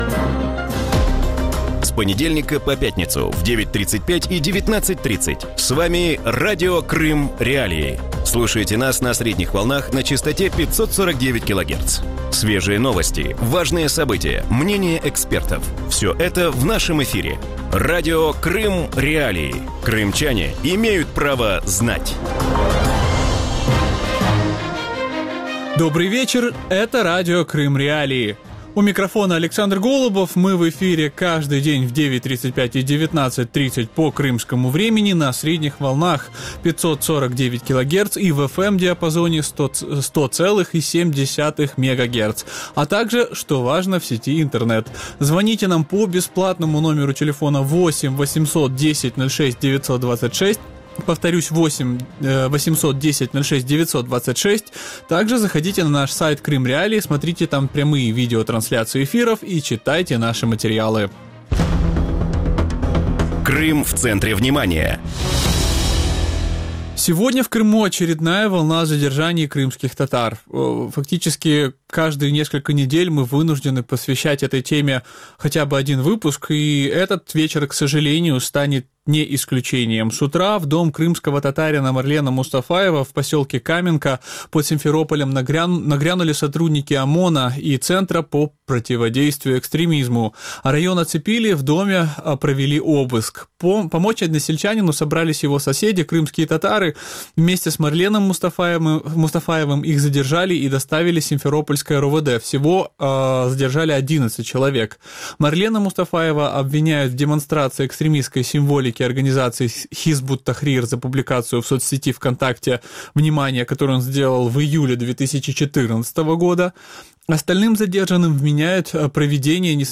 В вечернем эфире Радио Крым.Реалии говорят об очередной волне задержаний крымских татар на полуострове. За что сотрудники ФСБ задержали крымских татар в Симферополе и почему российские силовики не прекращают давление на крымскотатарскую общину на полуострове?